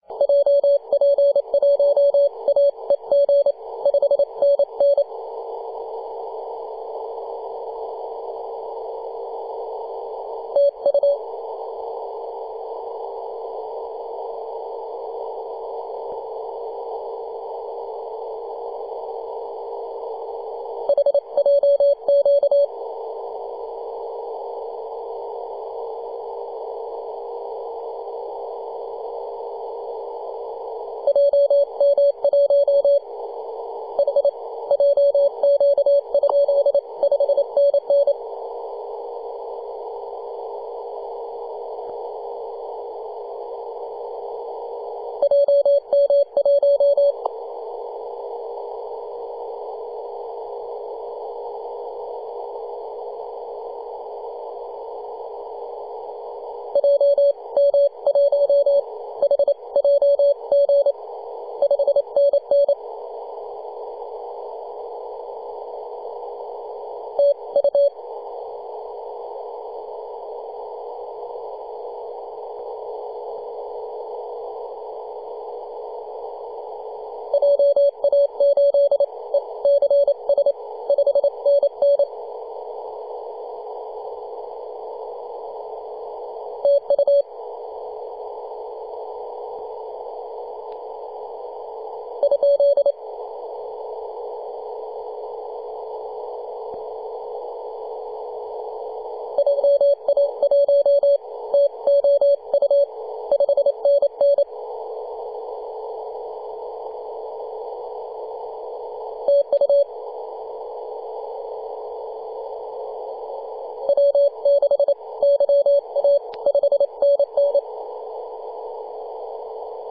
2015/May/15 2222z PW0F 21.026MHz CW